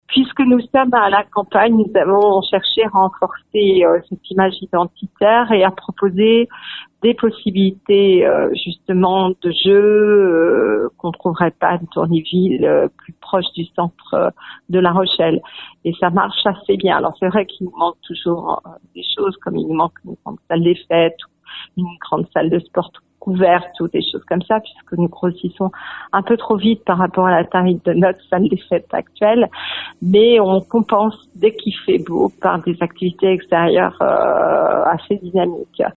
Voilà Sylvie Guerry-Gazeau, maire de Clavette, qui assistera à l’ouverture de la 6e classe à l’école Pierre Perret le 2 septembre prochain.